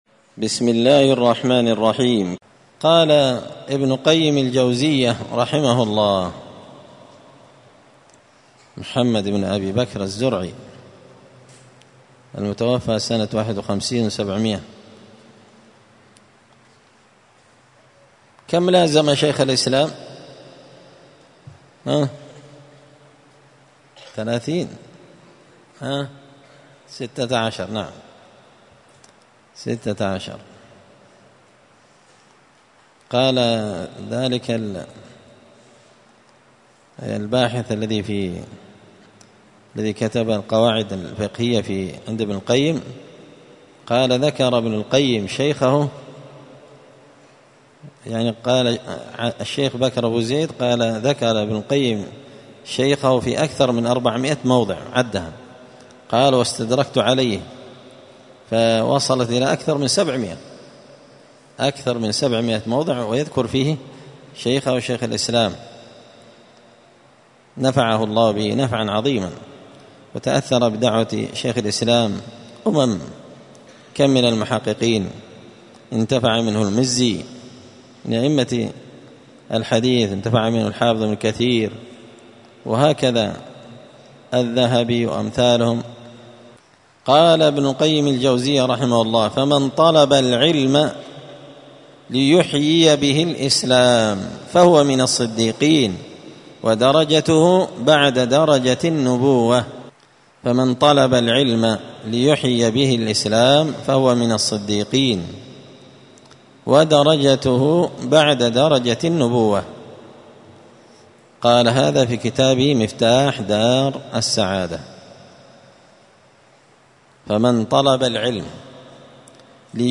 دار الحديث السلفية بمسجد الفرقان
*الدرس الخامس (5) تابع لآثار في باب فضل العلم*